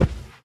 latest / assets / minecraft / sounds / mob / panda / step4.ogg
step4.ogg